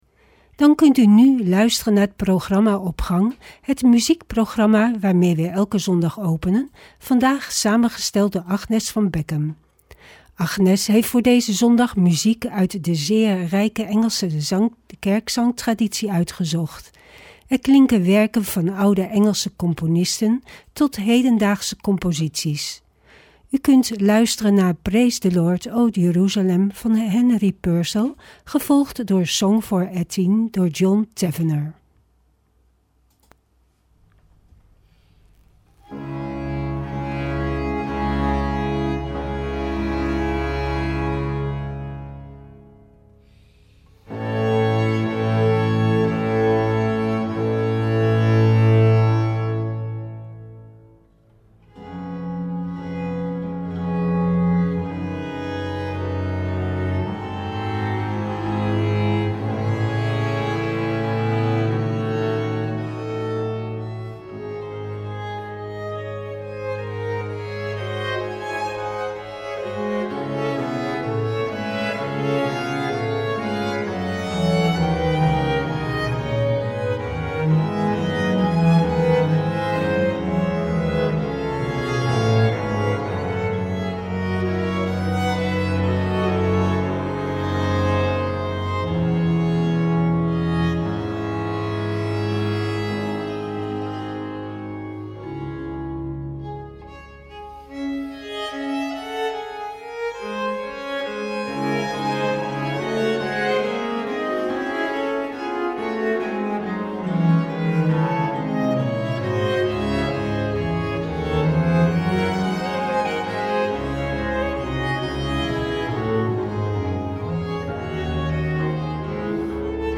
Wie kent niet de geweldige loepzuivere Engelse koren?